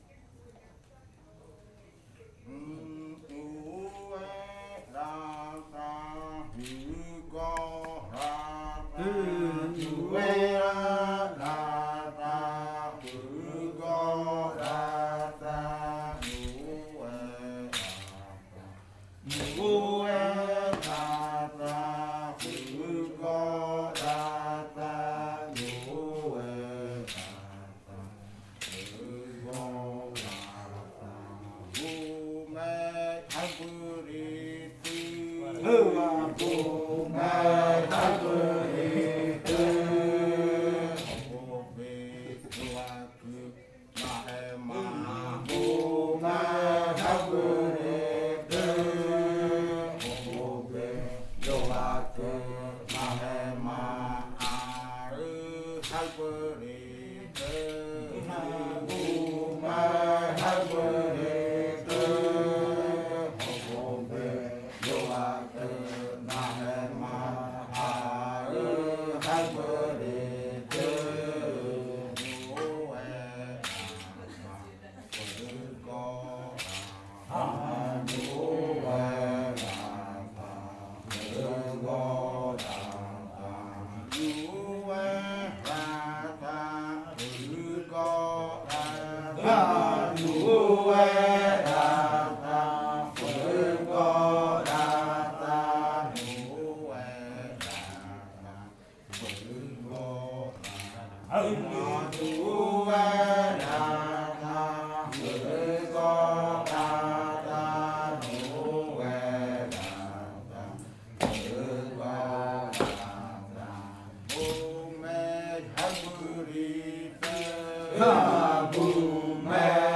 Canto de la variante muinakɨ
Leticia, Amazonas
con el grupo de cantores sentado en Nokaido.
with the group of singers seated in Nokaido. This song is part of the collection of songs from the Yuakɨ Murui-Muina ritual (fruit ritual) of the Murui people, performed by the Kaɨ Komuiya Uai Dance Group with the support of a solidarity outreach project by UNAL, Amazonia campus.